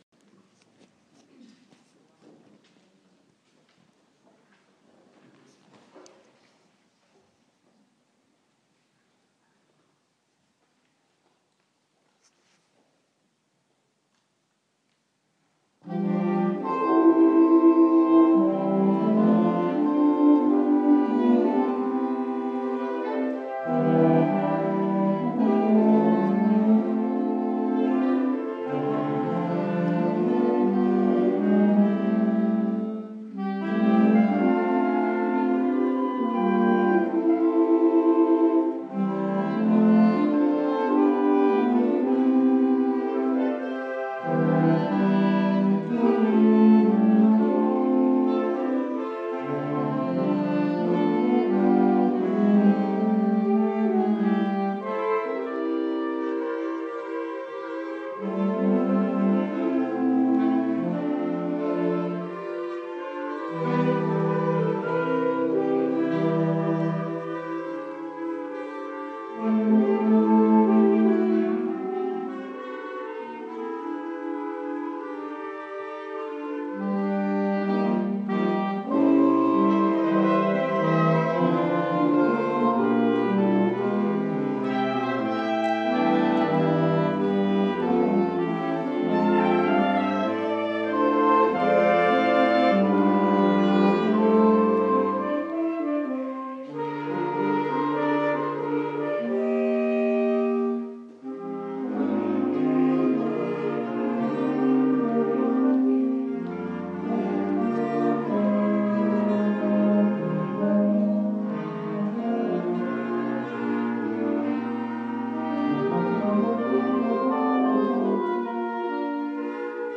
Adult Wind Band